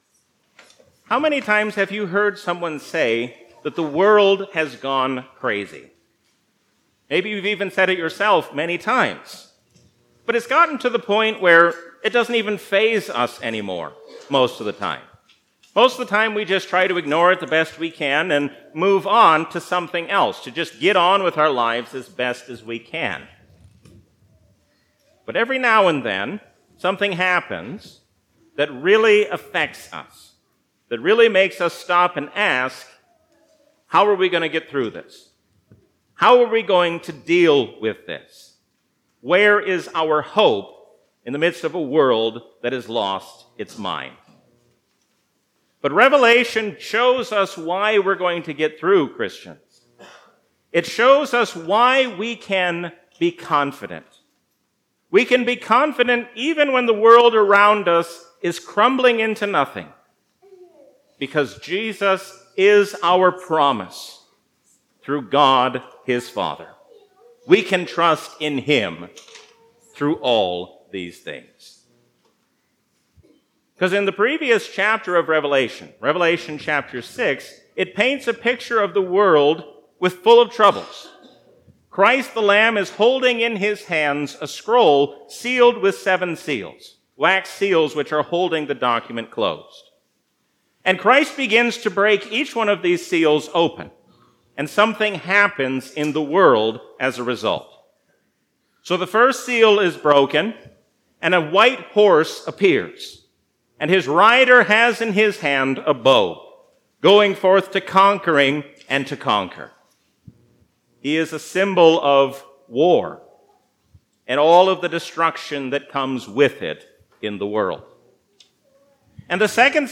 A sermon from the season "Trinity 2022." We can be confident even in the midst of a world which has lost its mind, because God promises us a great joy to come through Jesus Christ.